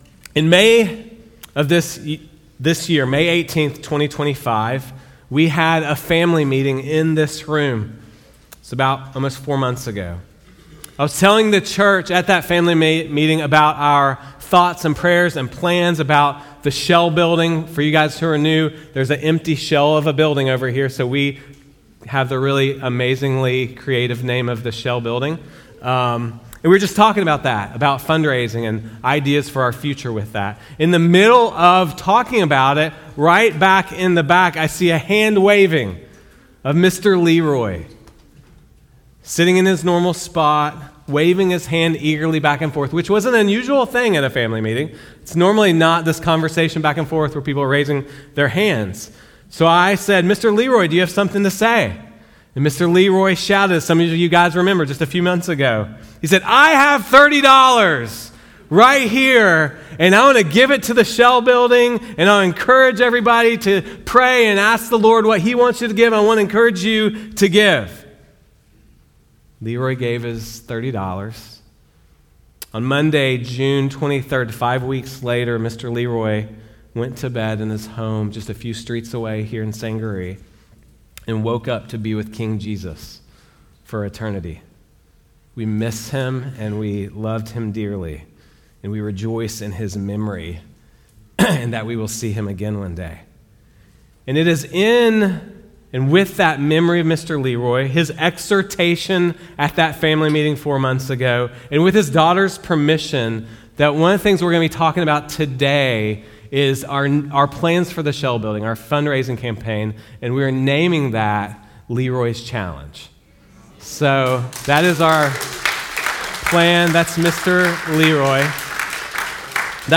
These sermons have been preached outside a normal sermon series at Risen Hope.